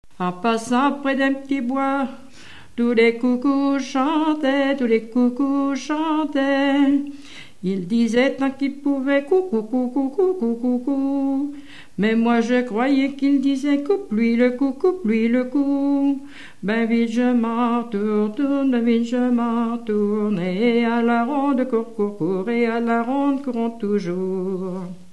ronde
Pièce musicale inédite